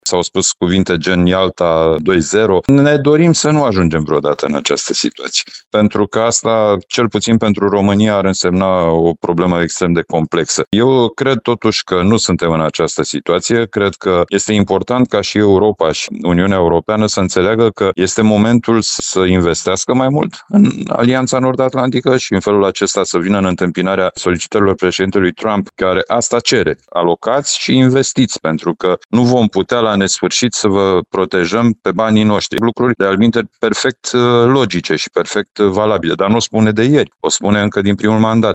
Fostul ministru al Apărării, Mihai Fifor, vicepreședintele Comisiei de Apărare din Camera Deputaților, a explicat, pentru Radio Timișoara, că parteneriatul transatlantic este esențial pentru securitatea și apărarea Europei, iar România trebuie să fie prezentă la aceste discuții.